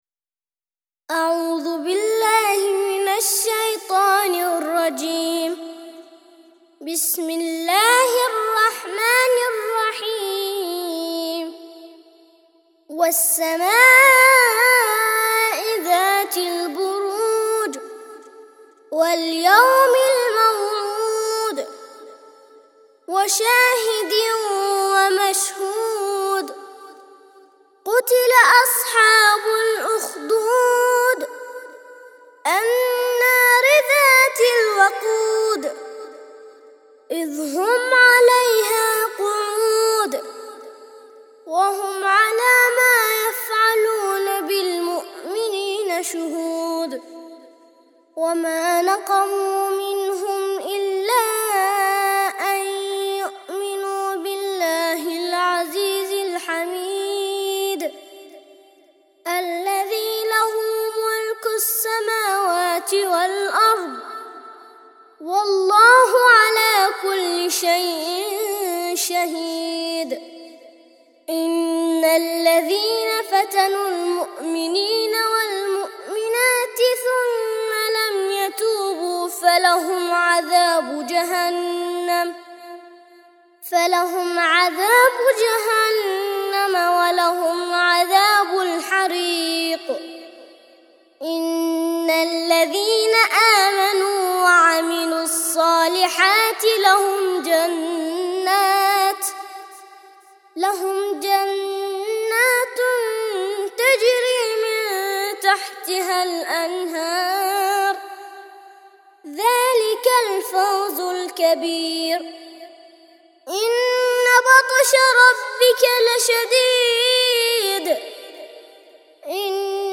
85- سورة البروج - ترتيل سورة البروج للأطفال لحفظ الملف في مجلد خاص اضغط بالزر الأيمن هنا ثم اختر (حفظ الهدف باسم - Save Target As) واختر المكان المناسب